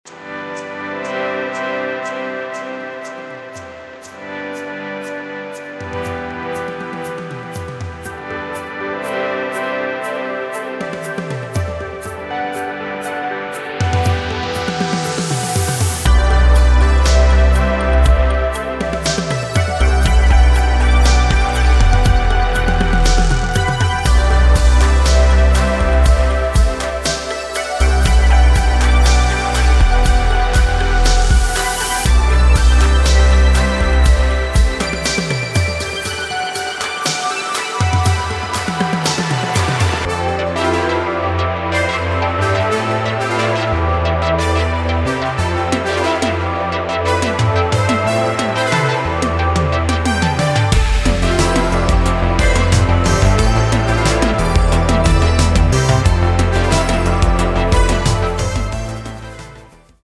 Synth-pop